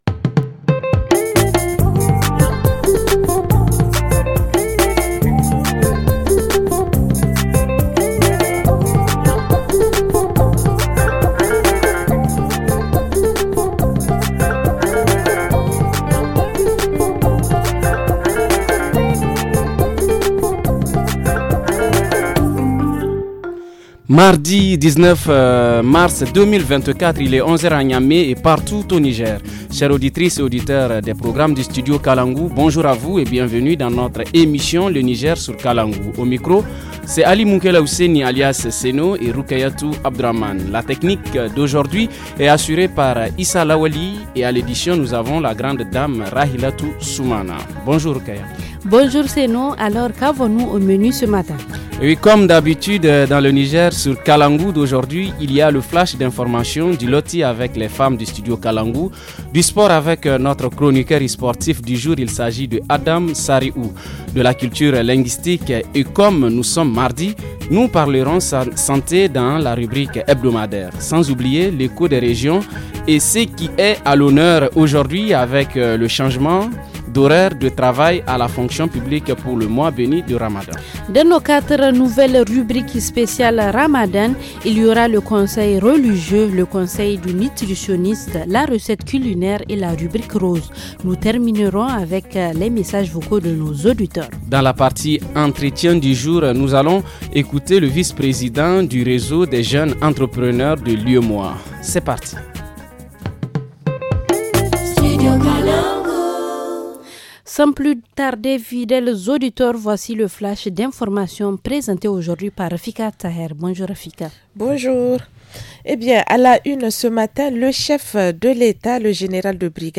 Rubrique hebdomadaire : Comment mieux manger, pour mieux jeûner ? Reportage région : Changement des horaires de travail à la fonction publique.